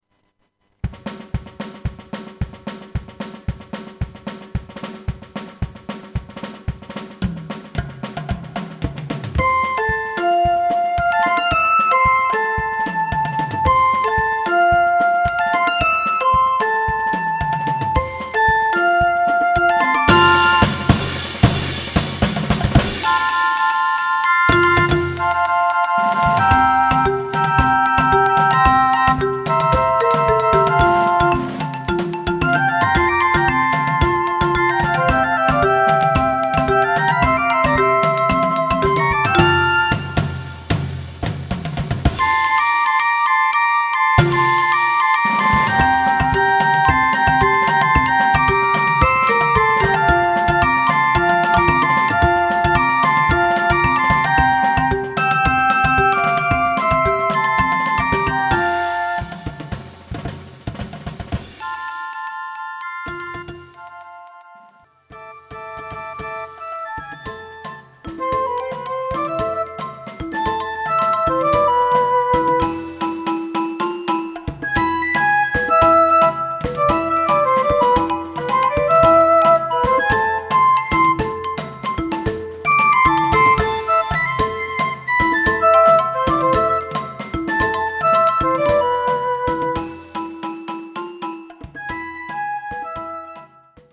een aanstekelijke medley
Fluiterkorps  –  pdf –
Moeilijkheidsgraad  –  gevorderd